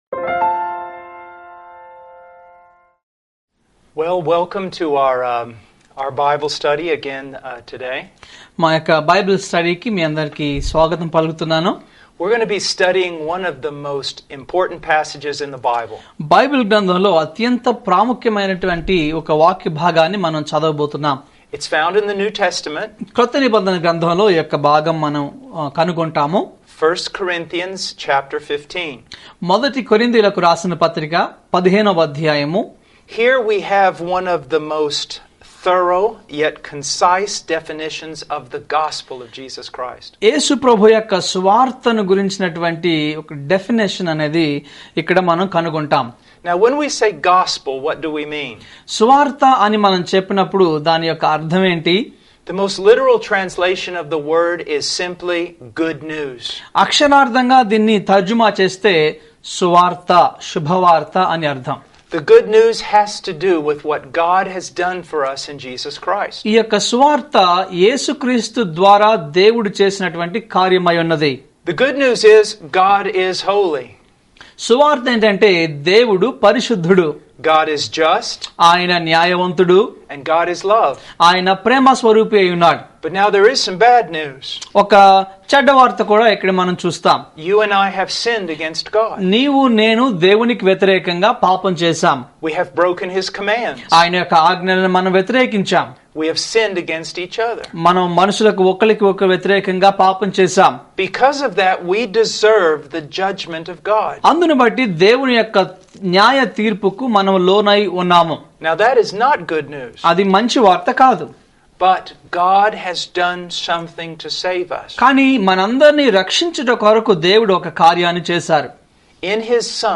ఫుల్ సేర్మోన్ (FS)